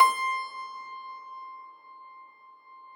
53k-pno18-C4.wav